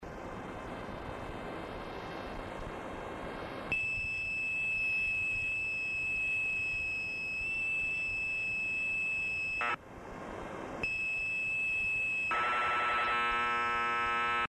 Lo único es que atenúa un poco la señal, esto es, se oye más bajito.